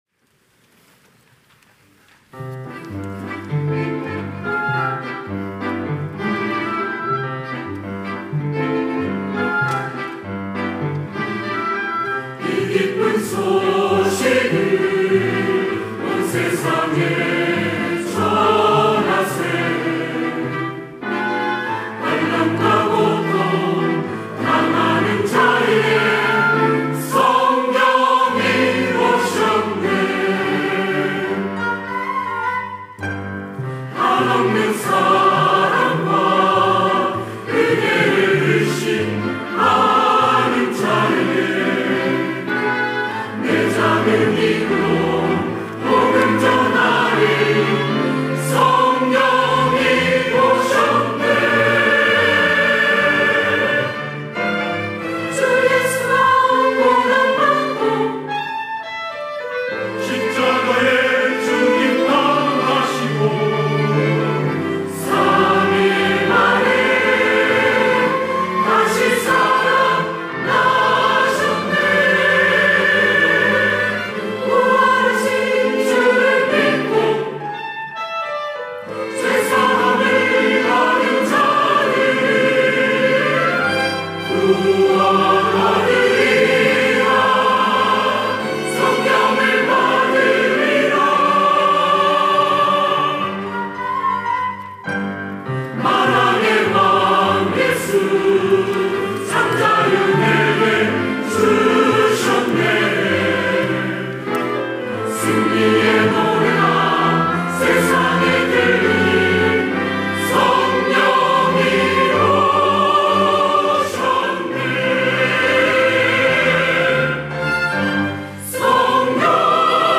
할렐루야(주일2부) - 이 기쁜 소식을
찬양대